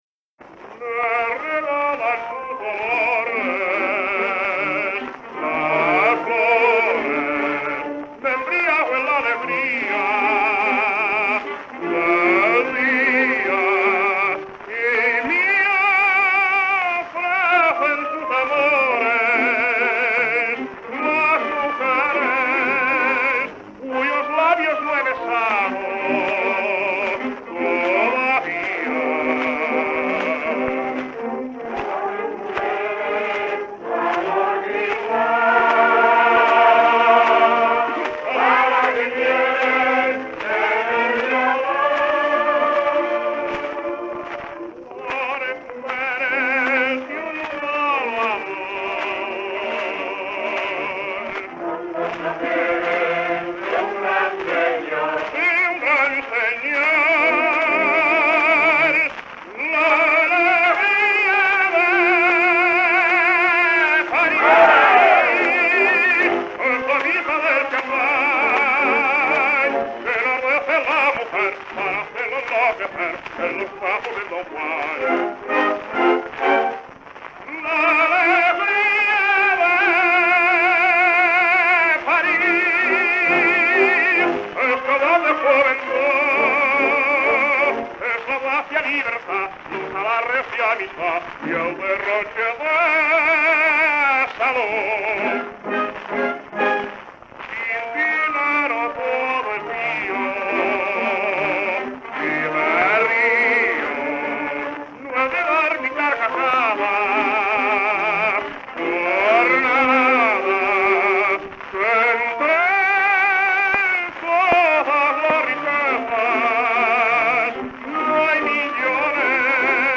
blues
orquesta
78 rpm